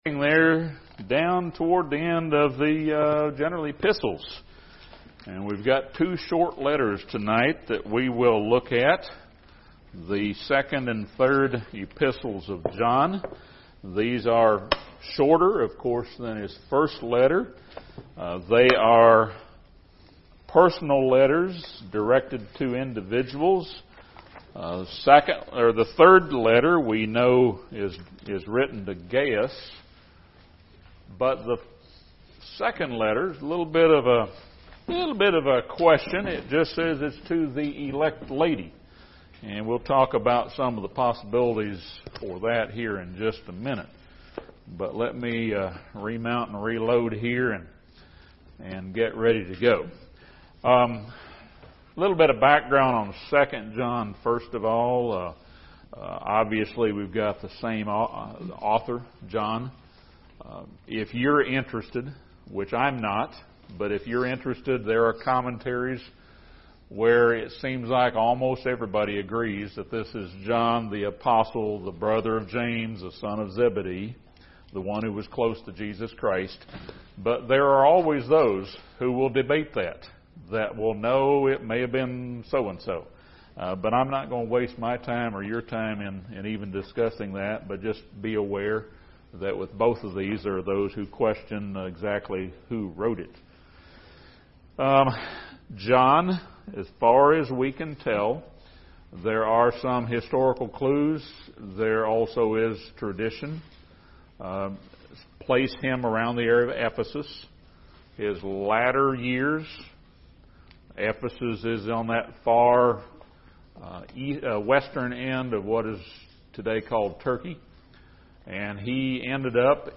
This Bible study surveys John's brief 2nd and 3rd letters.